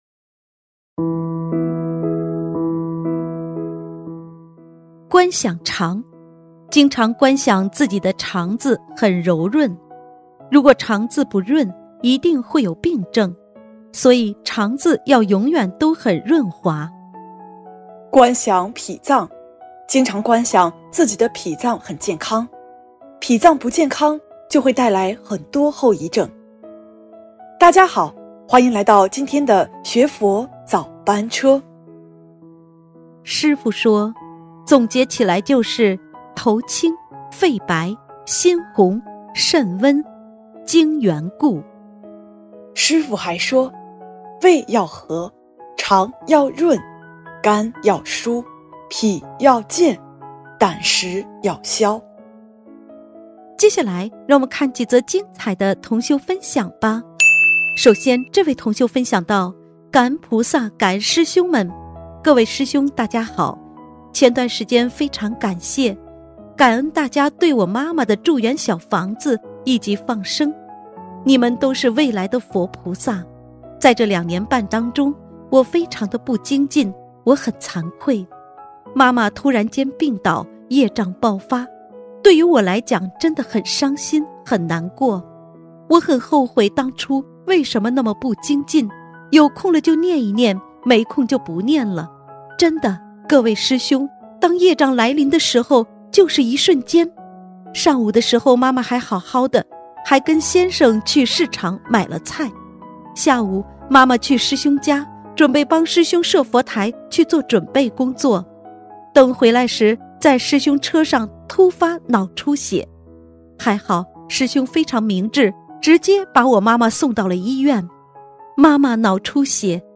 广播节目